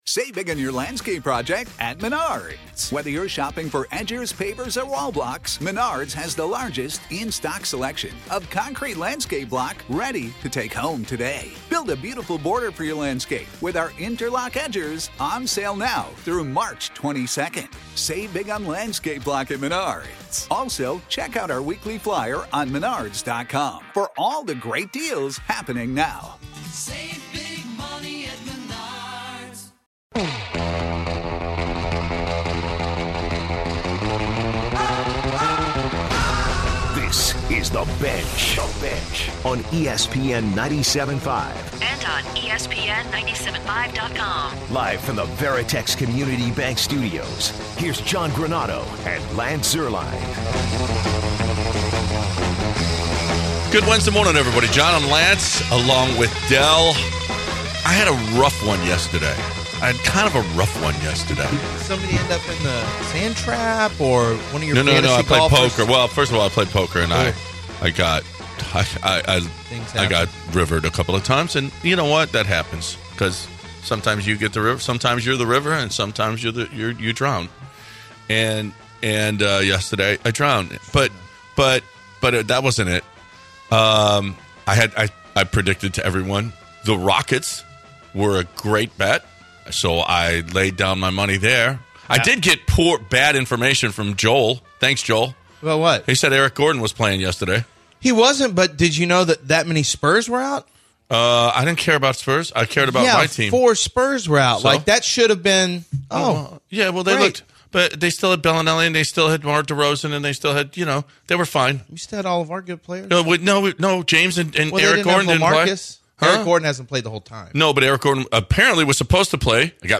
evaluate what the verdict on college football this season might be while taking calls from listeners.